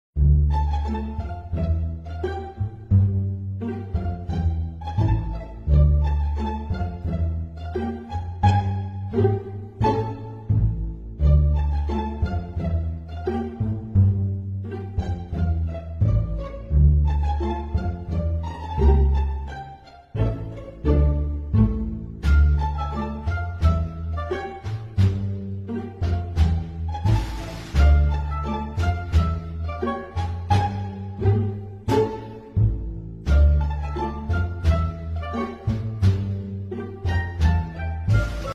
Mitten im Interview lässt Harlekin Macron seine Luxus-Uhr vom Handgelenk verschwinden und erklärt währenddessen, dass das Rentenmindestalter erhöht werden muss...